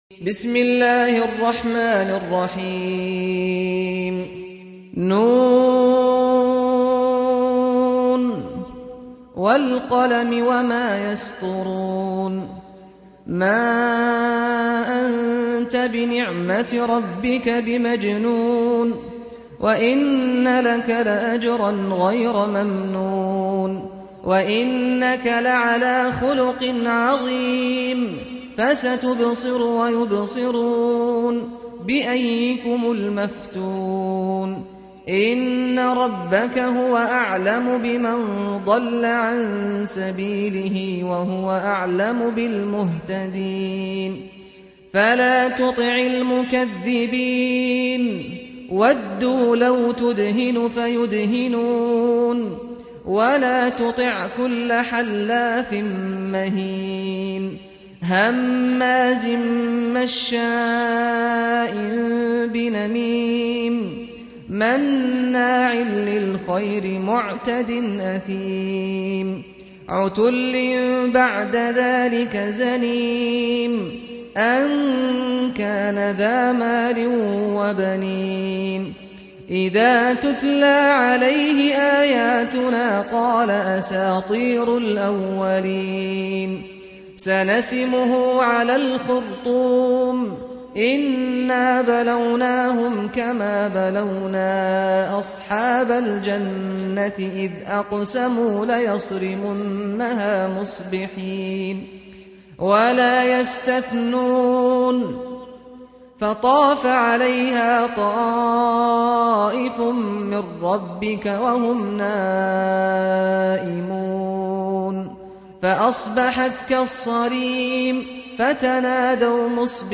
سُورَةُ القَلَمِ بصوت الشيخ سعد الغامدي